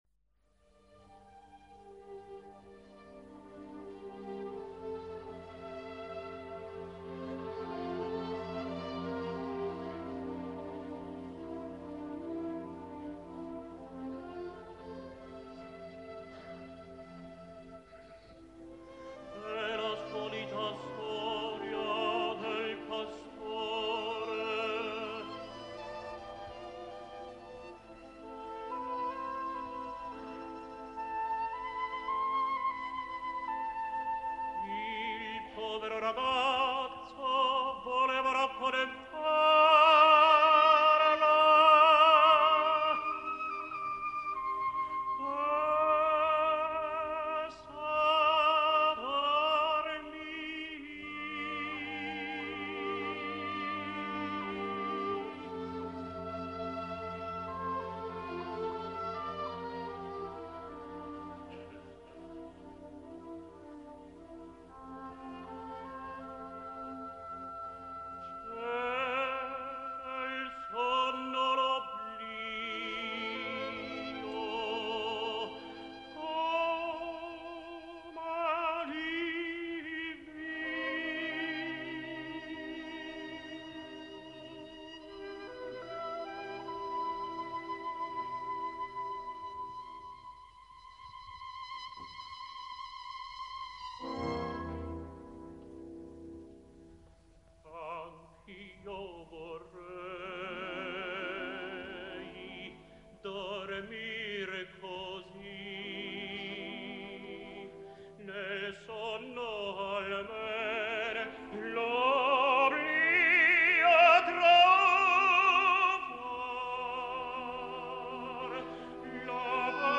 Luigi Infantino [Tenor - Italien - Oper - Repertoire - Aufnahmen]
Stimmlage: Tenor (Hörbeispiel: È la solita storia del pastore... aus Cilèas "L'Arlesiana" 10.02.1958 Mailand, Concerto Martini & Rossi